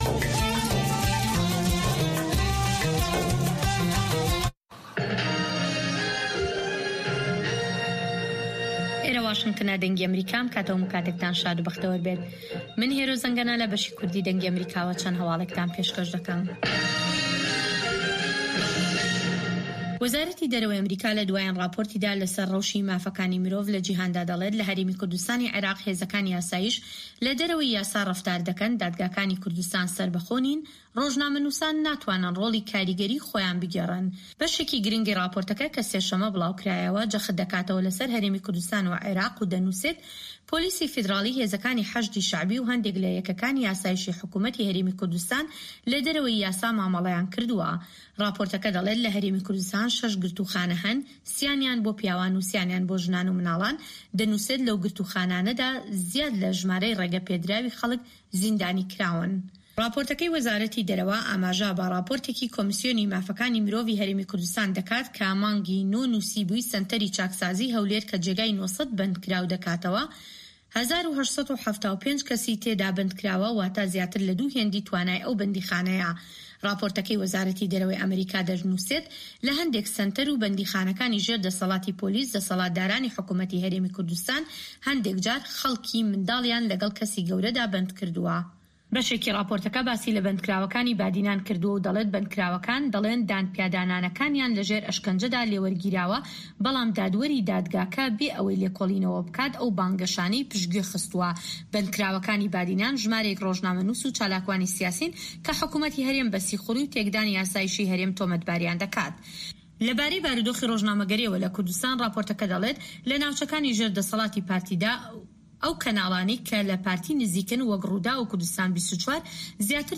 Nûçeyên Cîhanê 2